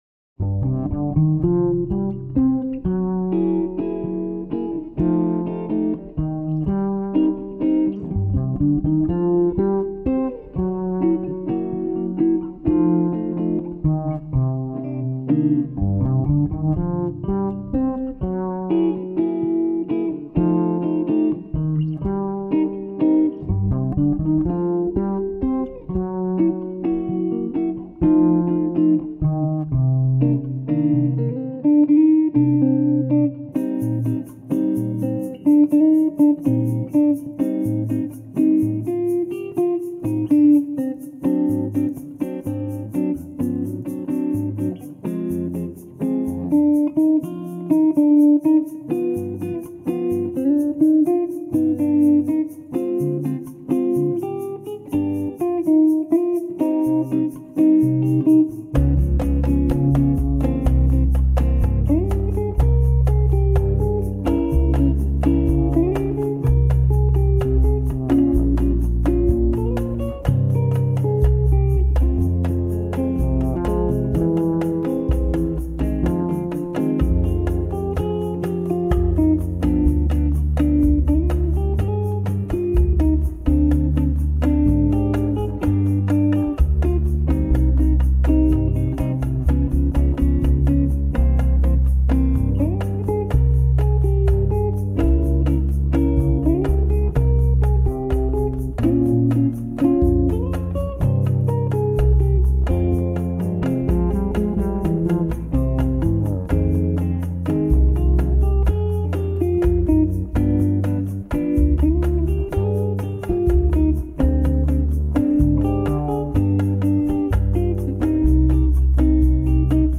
2957   05:06:00   Faixa: 10    Samba